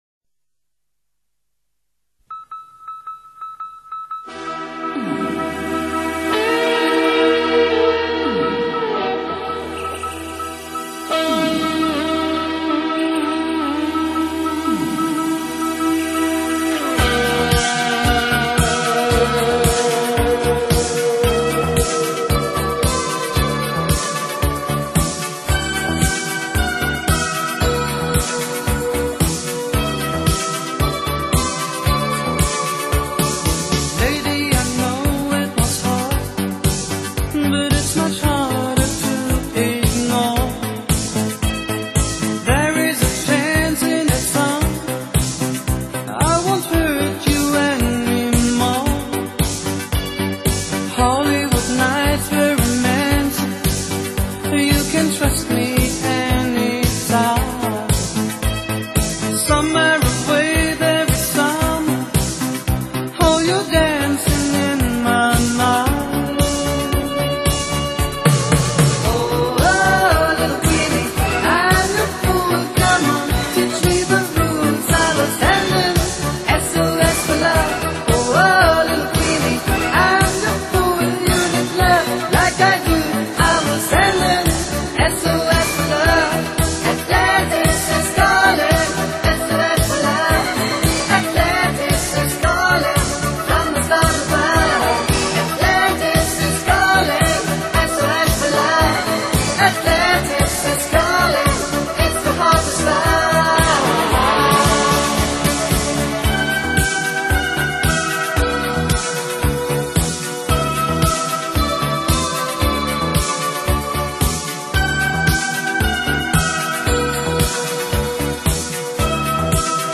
超级怀旧经典西洋舞曲